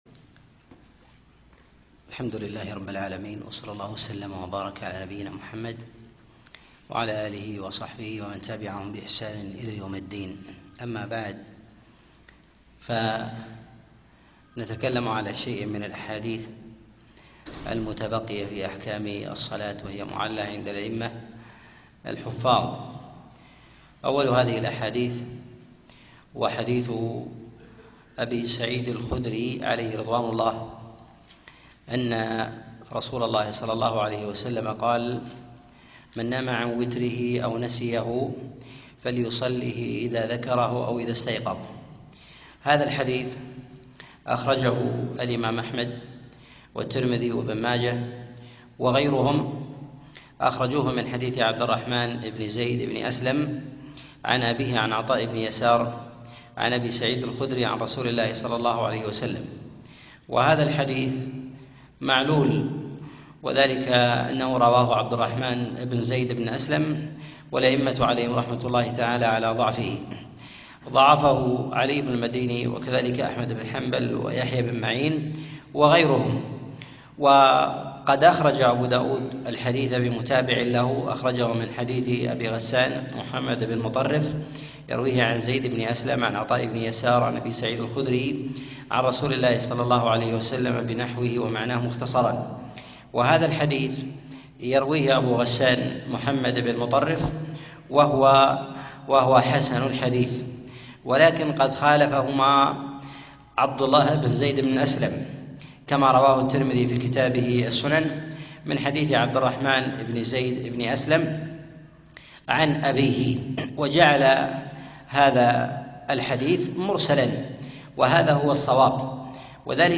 الأحاديث المعلة في الصلاة الدرس 24